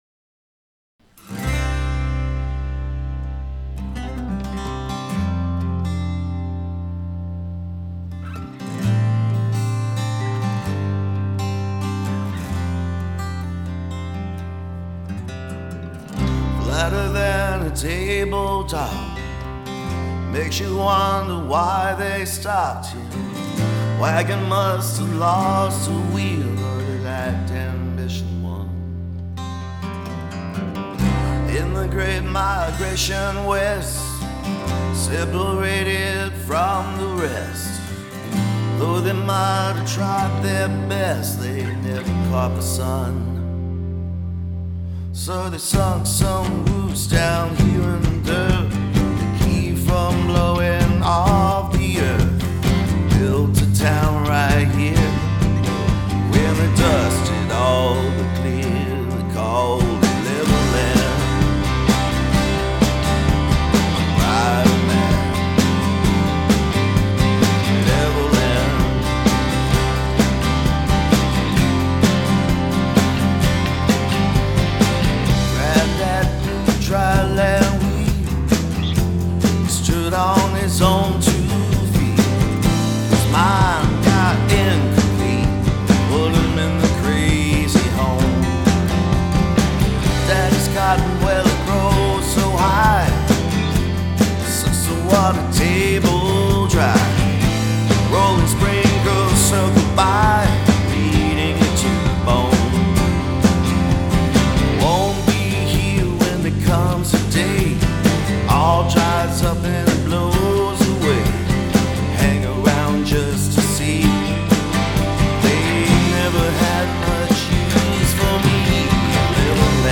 Here's a song an artist recorded here at my condo.
acoustic guitar and drums